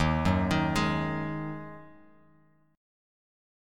D#mbb5 chord